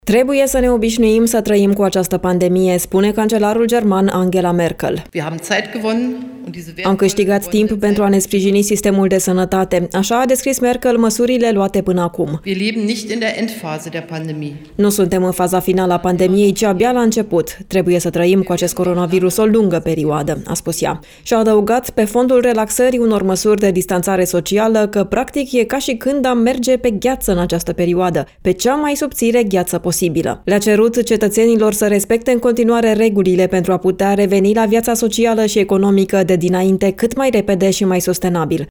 ”Nu suntem în faza finală a pandemiei, ci în continuare la început”, a subliniat Angela Merkel, prezentă în Bundestag.